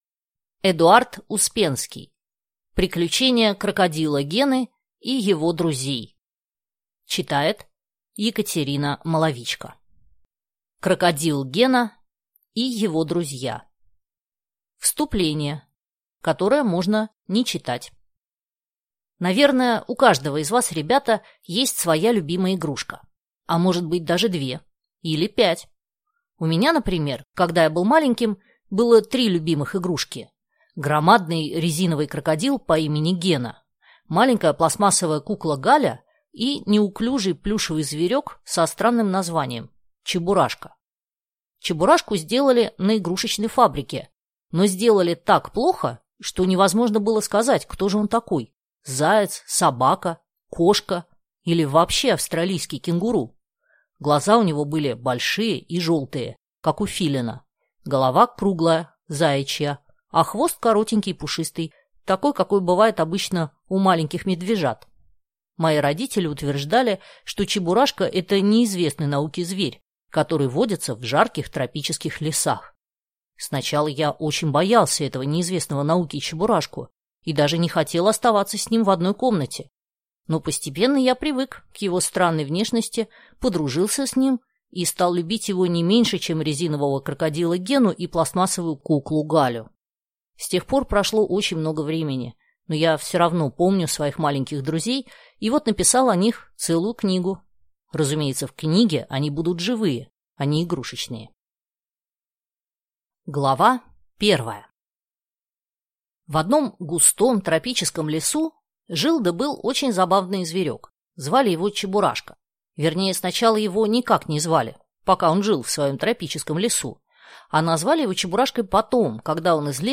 Аудиокнига Приключения крокодила Гены и его друзей | Библиотека аудиокниг